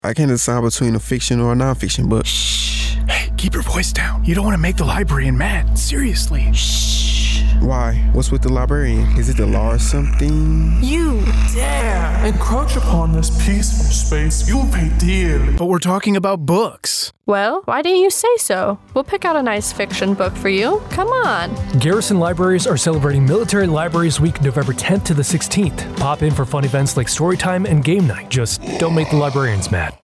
Radio Spot - Military Library Week